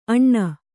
♪ aṇṇa